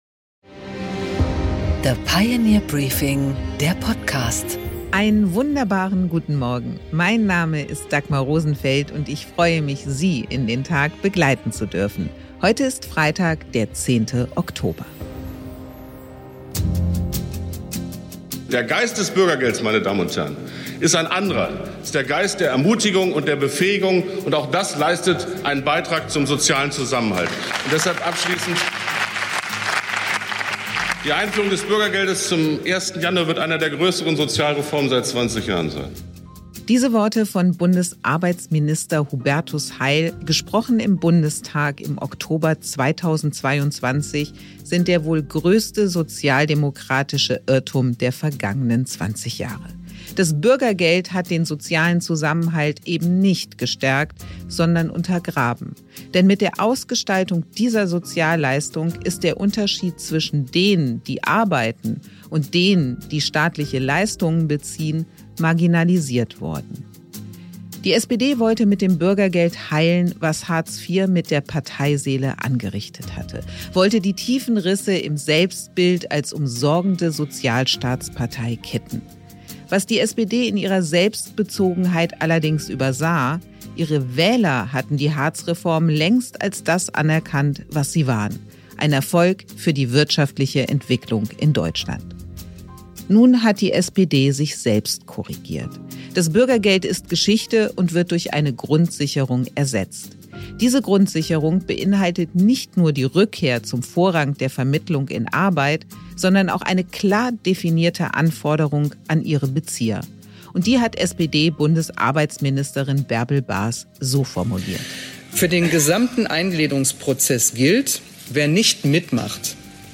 Interview mit Norbert Röttgen